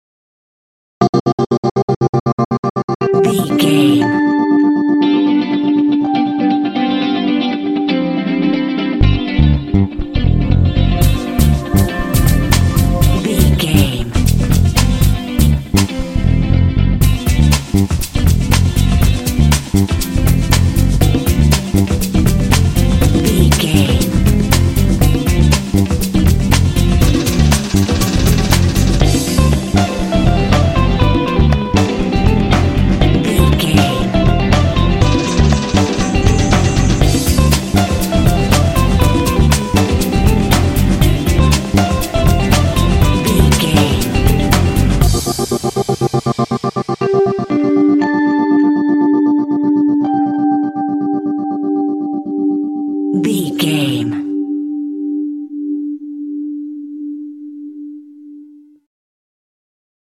Mixolydian
driving
energetic
groovy
synthesiser
drums
bass guitar
electric guitar
alternative rock
indie